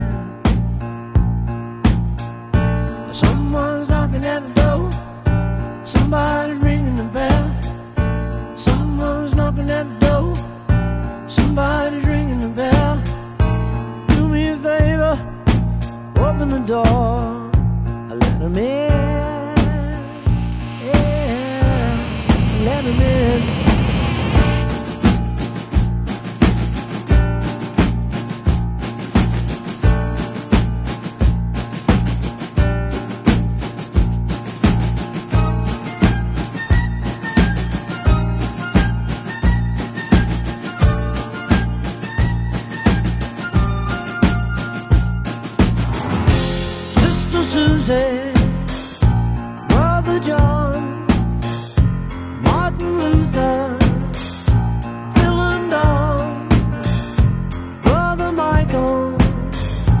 Yay sound effects free download